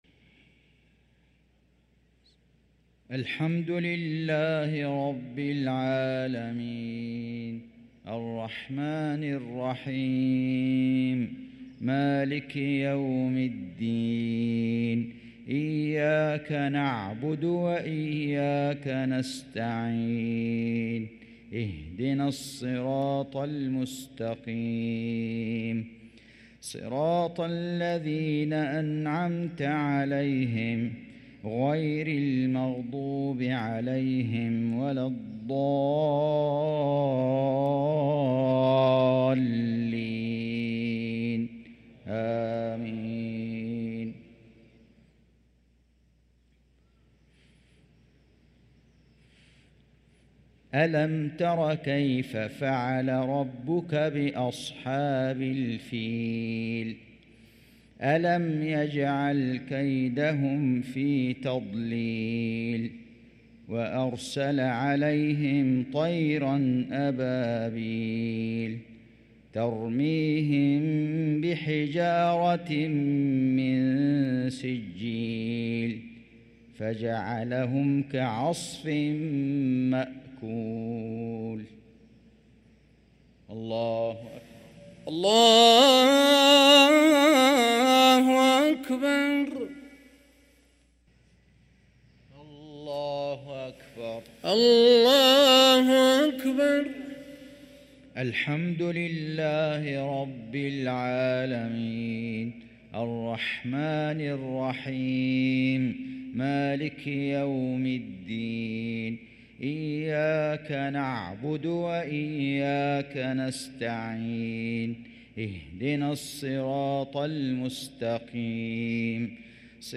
صلاة المغرب للقارئ فيصل غزاوي 8 ربيع الآخر 1445 هـ
تِلَاوَات الْحَرَمَيْن .